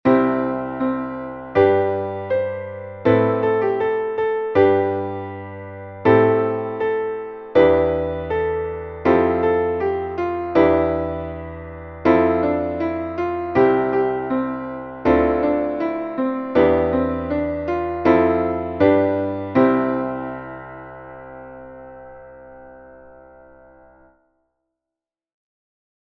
Traditionelles Kirchenlied